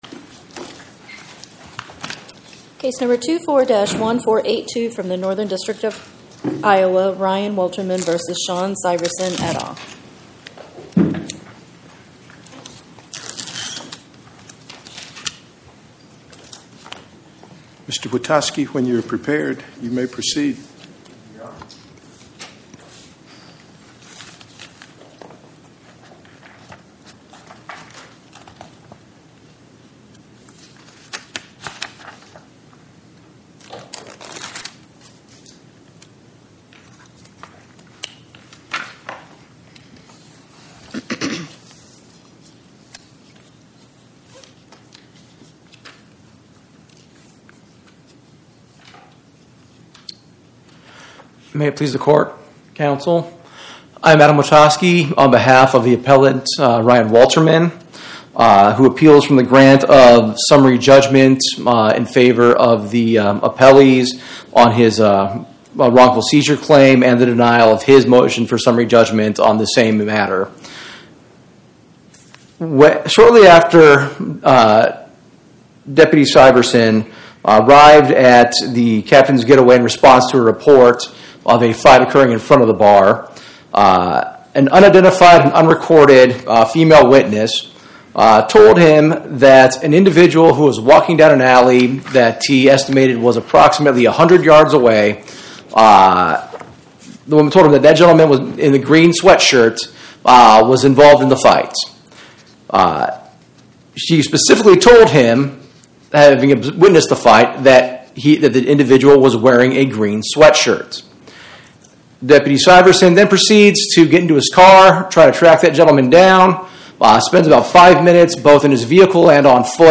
Oral argument argued before the Eighth Circuit U.S. Court of Appeals on or about 09/17/2025